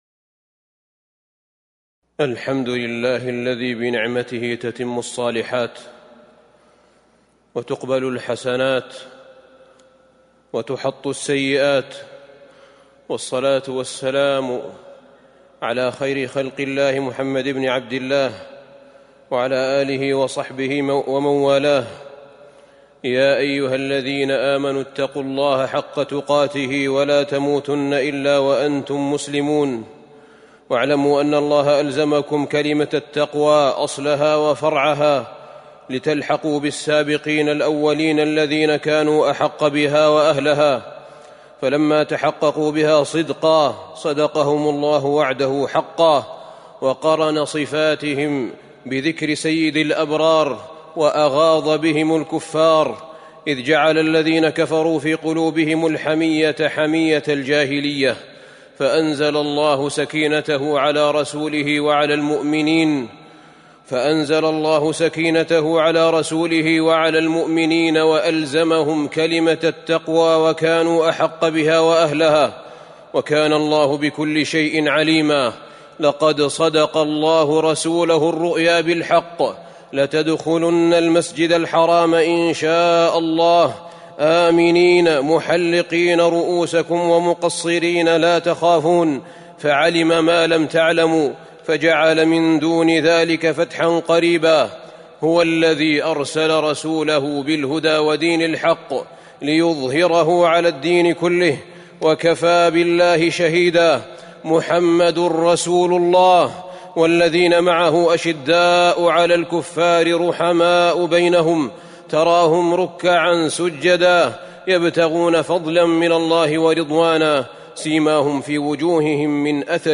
تاريخ النشر ١٣ ذو الحجة ١٤٤٢ هـ المكان: المسجد النبوي الشيخ: فضيلة الشيخ أحمد بن طالب بن حميد فضيلة الشيخ أحمد بن طالب بن حميد الكلمة الطيبة